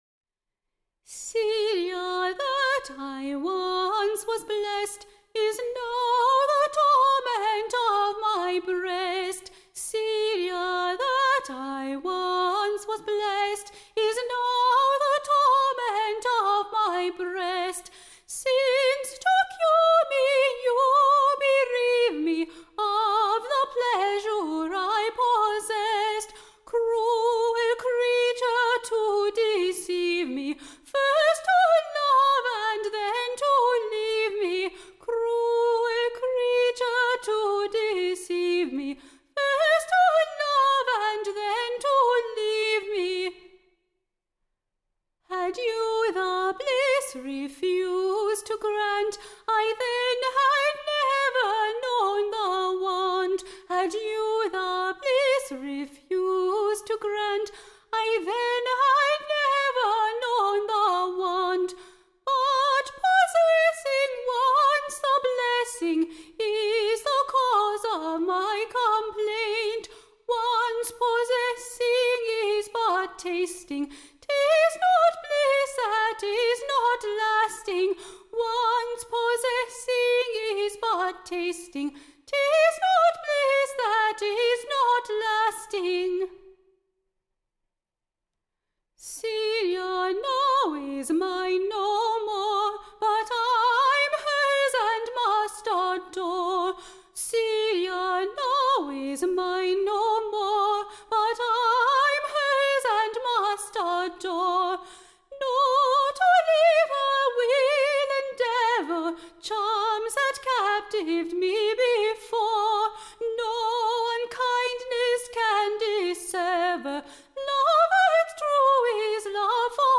Recording Information Ballad Title Coy Celia's Cruelty; / OR, / The Languishing Lovers Lamentation: / BEING / The last New Play-Song sung at the Theatre-Royal, in a New Play called Amphitrion.